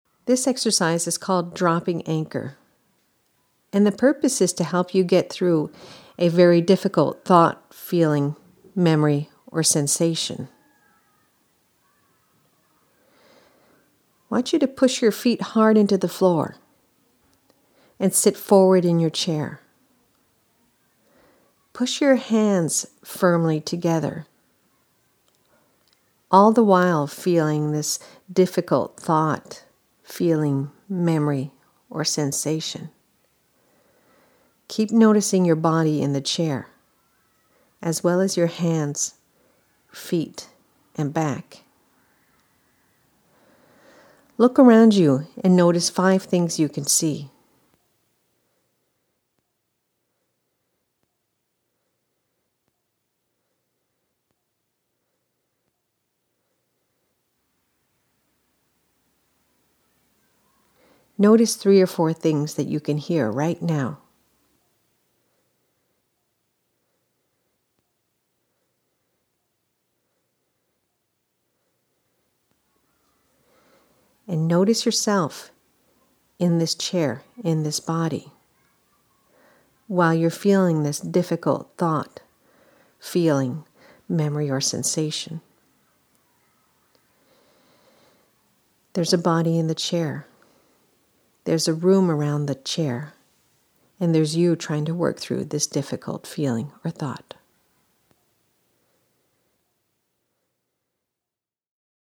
Il s'agit d'un parmi plusieurs exercices audio basés sur des concepts de la Thérapie d'acceptation et d'engagement ("ACT", en anglais).